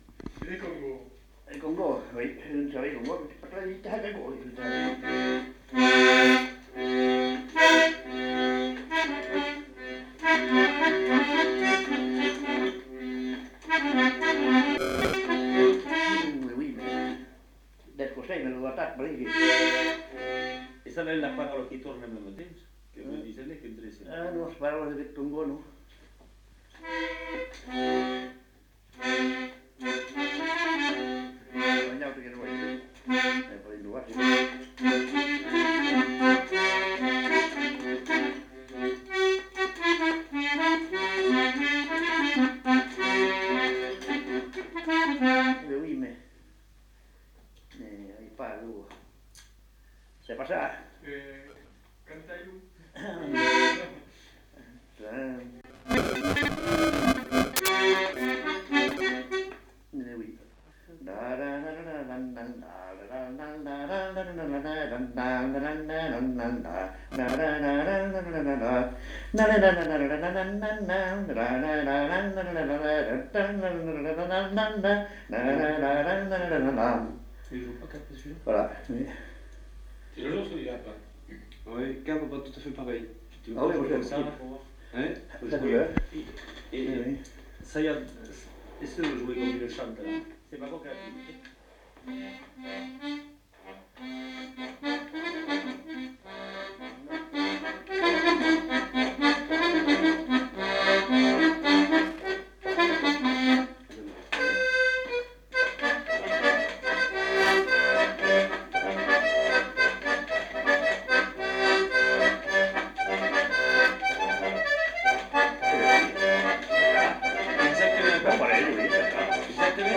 Lieu : Lencouacq
Genre : morceau instrumental
Instrument de musique : accordéon diatonique
Danse : congo